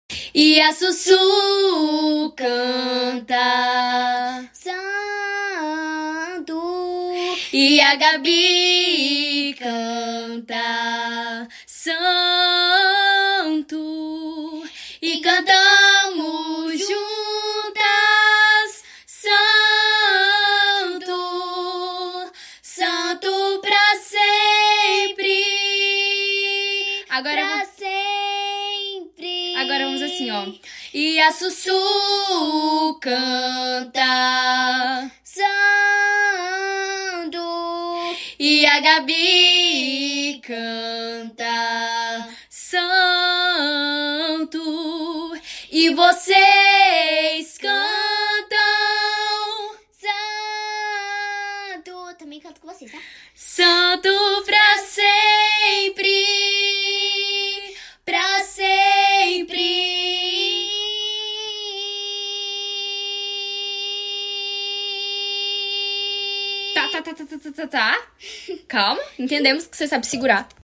O áudio ficou estralo ( muito alto )